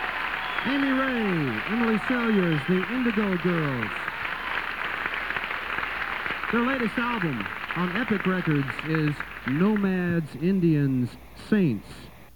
lifeblood: bootlegs: 1991-04-07: capitol theater - charleston, west virginia (mountain stage) (alternate)
05. announcer (0:12)
(radio broadcast)